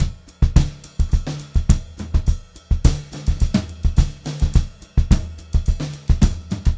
Index of /90_sSampleCDs/USB Soundscan vol.38 - Funk-Groove Drumloops [AKAI] 1CD/Partition E/03-106GROOV2